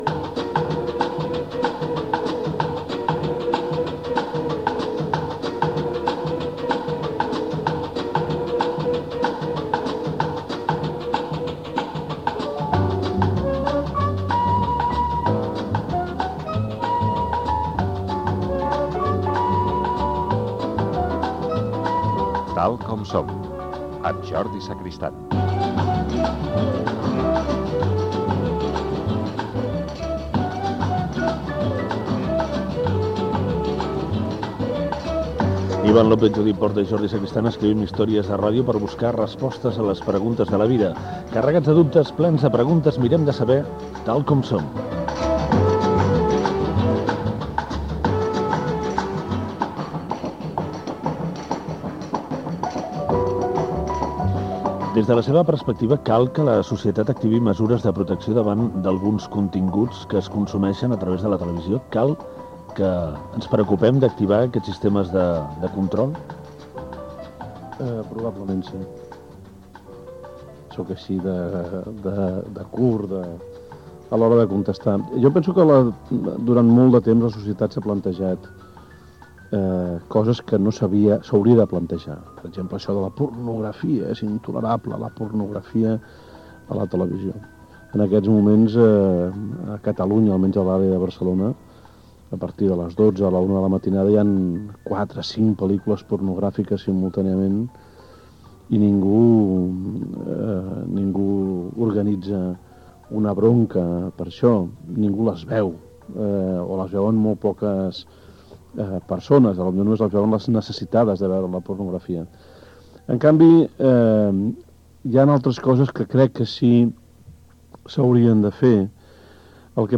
Crèdits i fragment d'una entrevista al periodista i expert televisiu Ramon Colom
Entreteniment